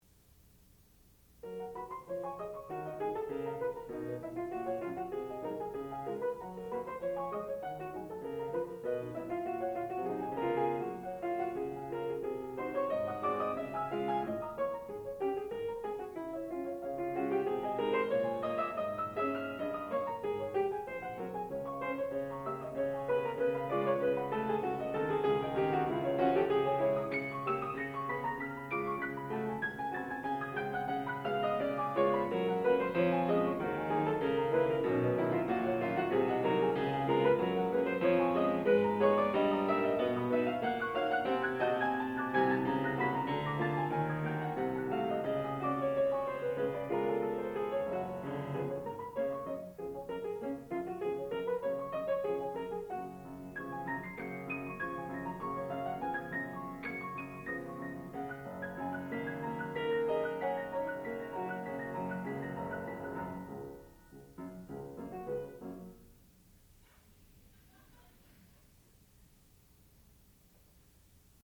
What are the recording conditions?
Student Recital